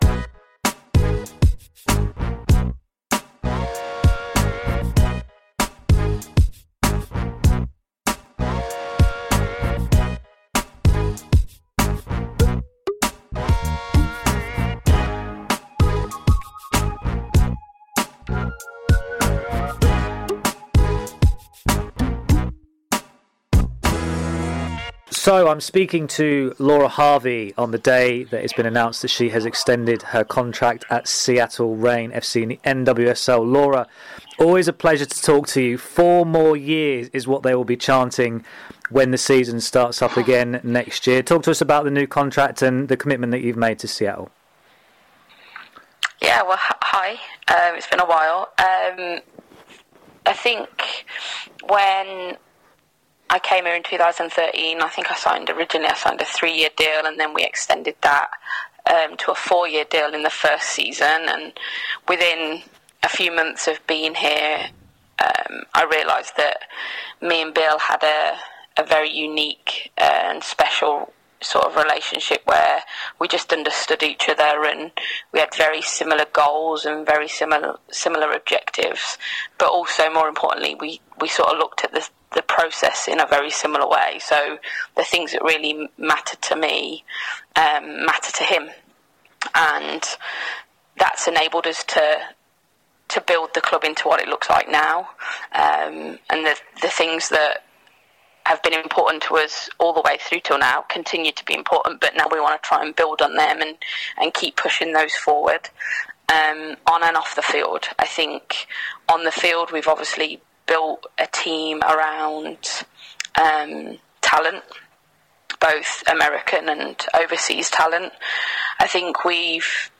On the day she signed a new 4-year-contract with Seattle Reign, Head Coach Laura Harvey talks to Women's Soccer Zone.